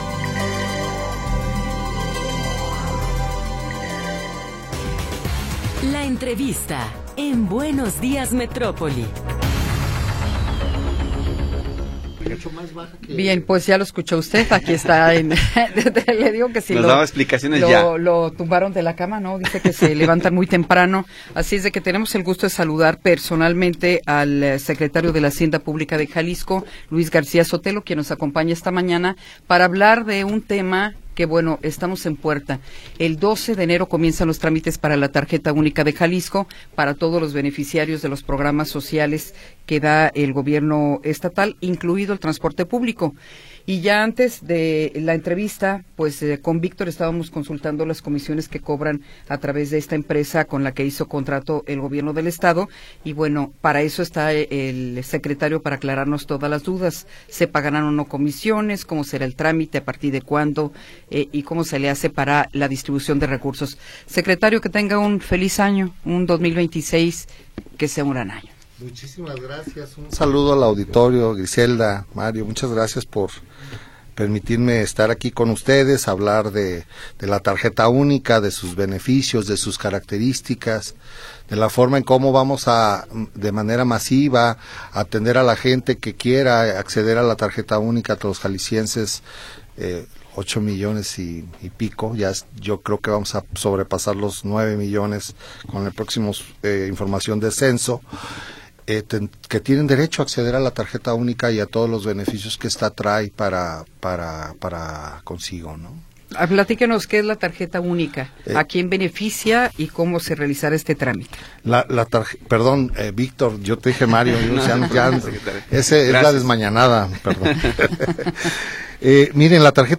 Entrevista con Luis García Sotelo
Luis García Sotelo, secretario de la Hacienda Pública del Estado, nos habla sobre la Tarjeta Única de Jalisco.